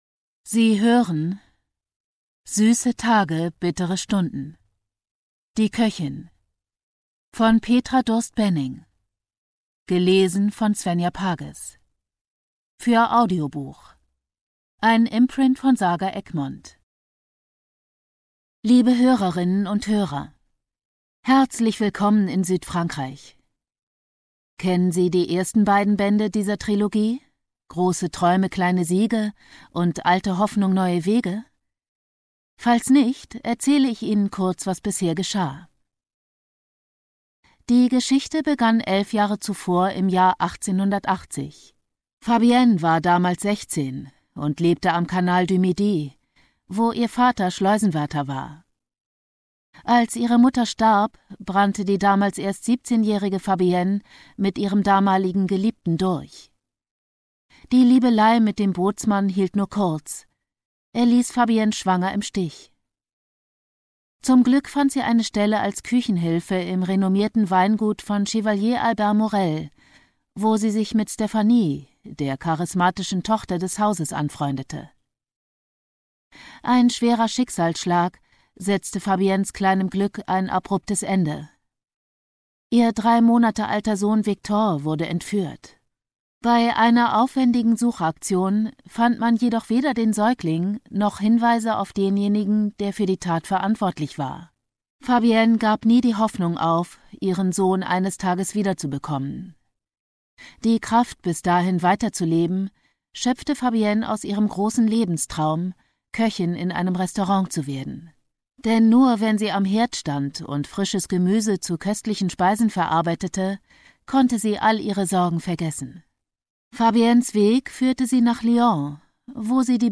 Genre: Literatur, Unterhaltung
Produktionsart: ungekürzt